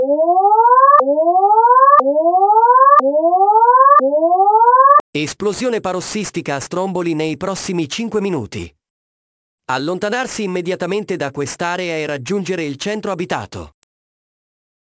Ascolta audio sirena esplosioni parossistiche (per la zona costiera) - SALT Eolie ( 2565 Kb ) Ascolta audio sirena esplosioni parossistiche (per i sentieri) - SALT Eolie ( 2565 Kb ) Ascolta audio sirena maremoto ( 1795 Kb )
paroxysm-trail-ita.wav